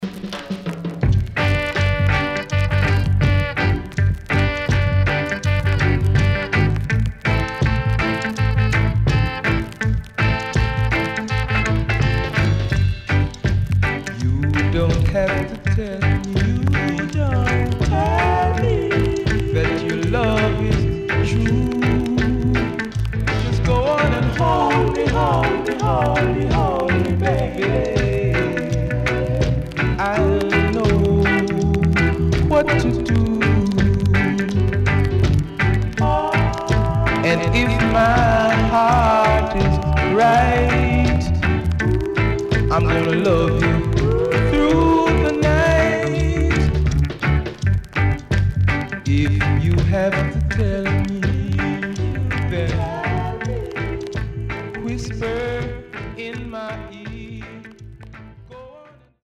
CONDITION SIDE A:VG〜VG(OK)
SIDE A:所々チリノイズ、プチノイズ入ります。